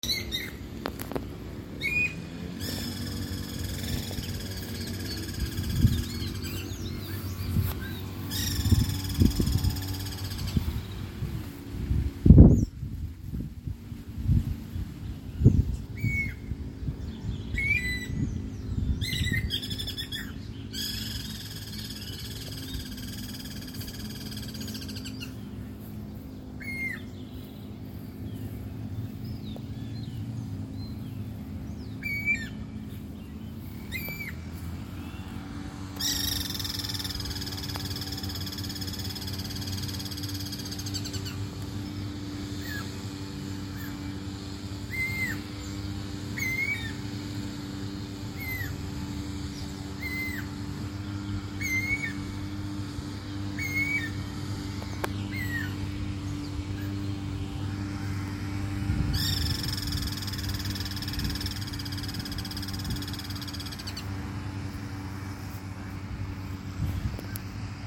Pirincho (Guira guira)
Nombre en inglés: Guira Cuckoo
Fase de la vida: Juvenil
Localidad o área protegida: San Miguel, capital
Condición: Silvestre
Certeza: Vocalización Grabada